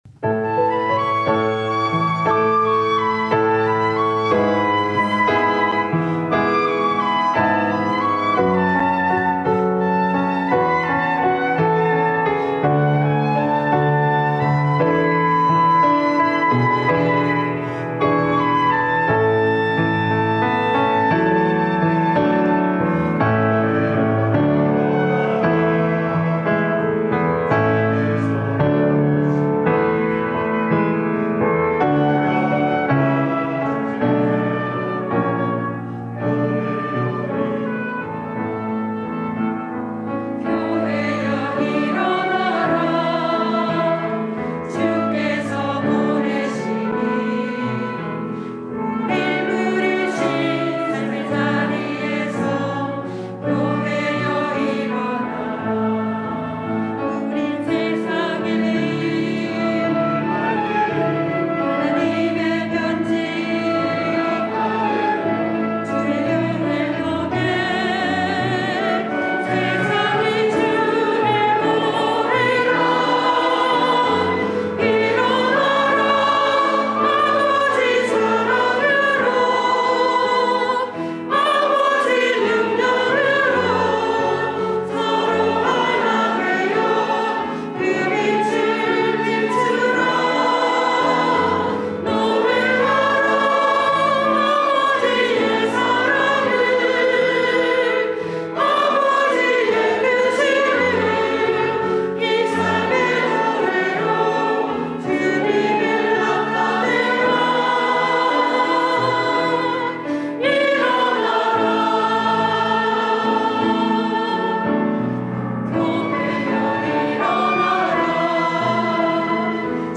1부 찬양대